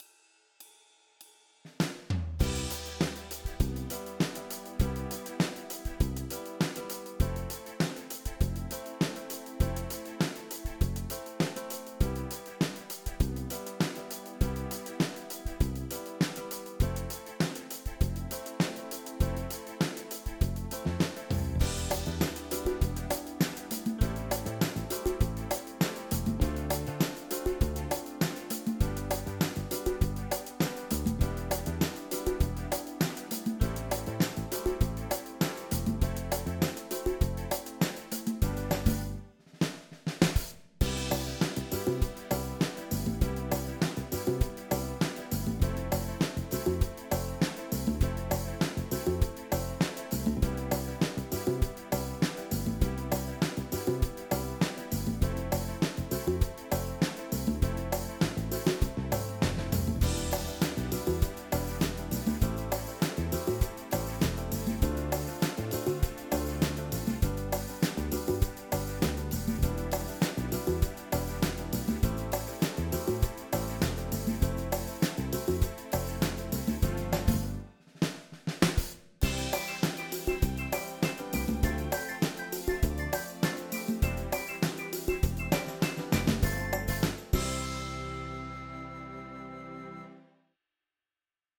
Drums from Toontrack Superior Drummer 2
Percussions from Musyng Kite GM.sfpack
Accordion from Accordions Library for Kontakt. All the rest from sampled Motif XF.
The demos itselves sound quite good.